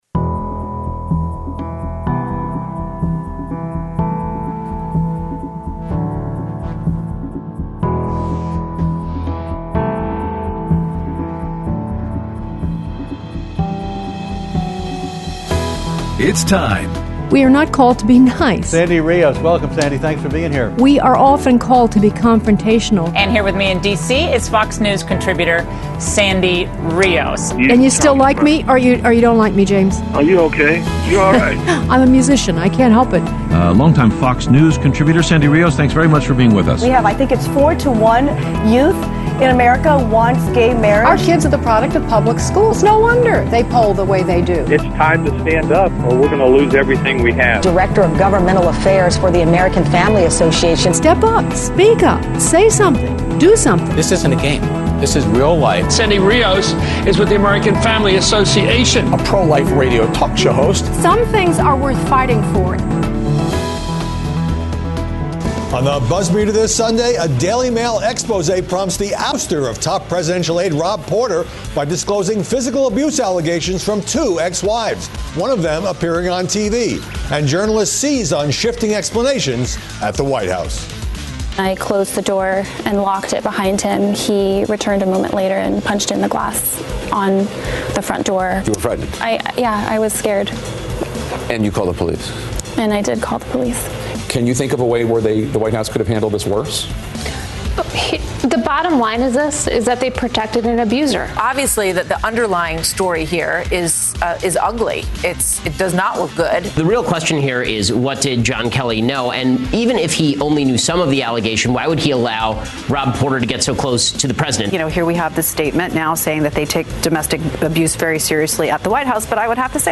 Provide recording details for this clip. Aired Friday 2/16/18 on AFR 7:05AM - 8:00AM CST